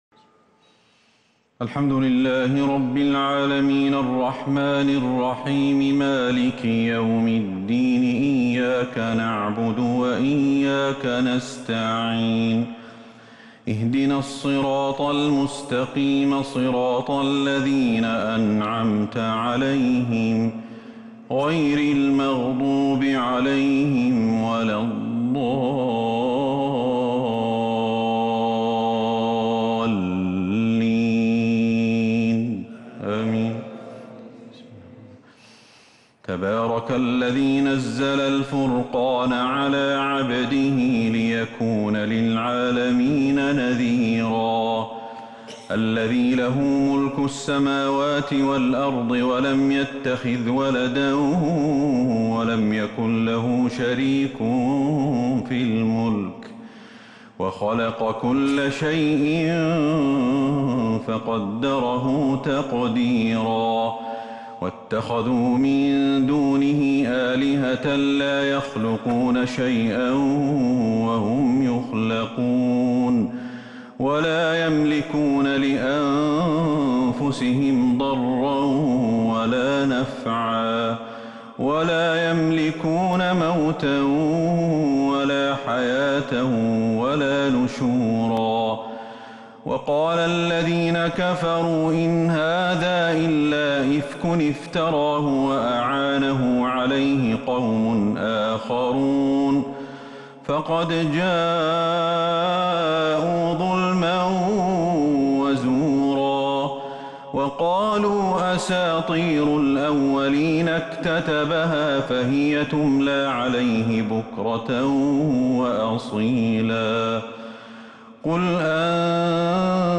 سورة الفرقان كاملة من تراويح الحرم النبوي 1442هـ > مصحف تراويح الحرم النبوي عام 1442هـ > المصحف - تلاوات الحرمين